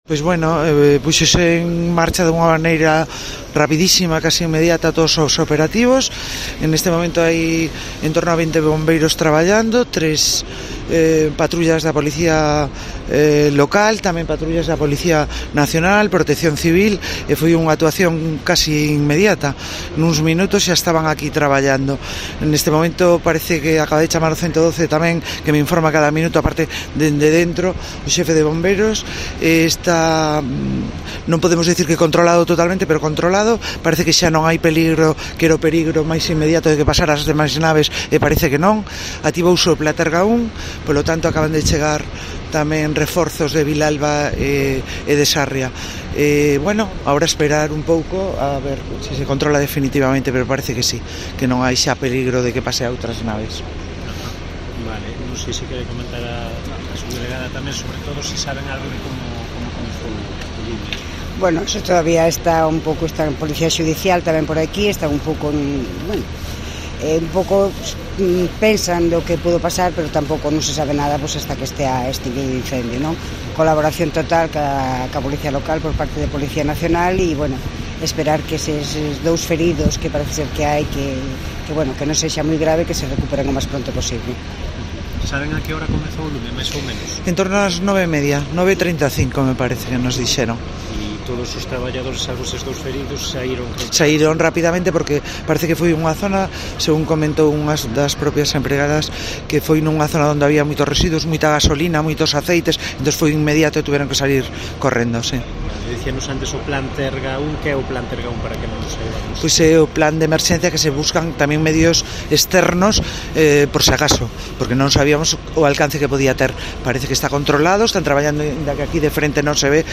Así lo ha comunicado en declaraciones a los medios en las inmediaciones de la nave la alcaldesa de la ciudad, Paula Alvarellos, que ha señalado también que el incendio, aunque "no está controlado totalmente", ya no hay peligro de que pase a otras naves próximas.